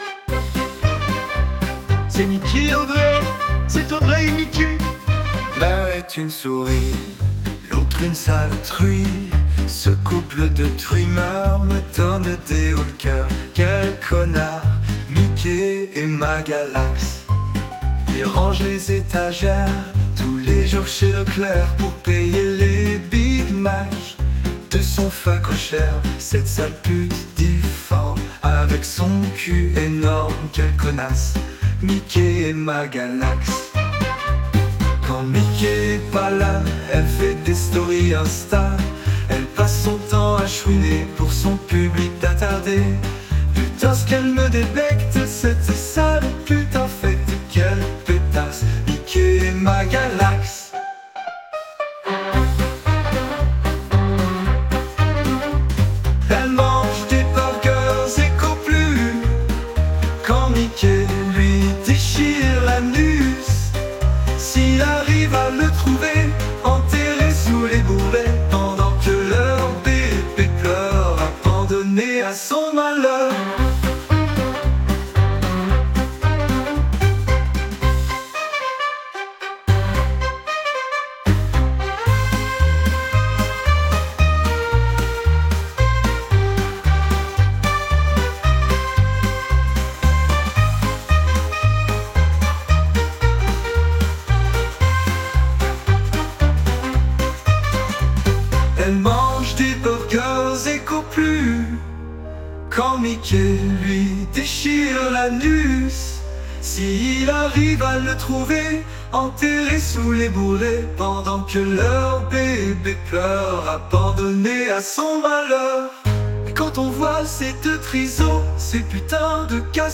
Chanson IA